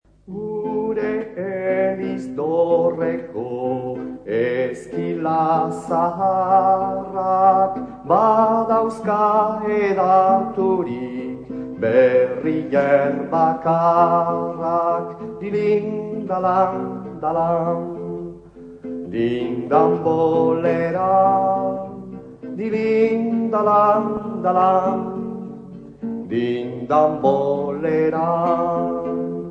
Kanpai-hotsa gogorarazten du beste honek: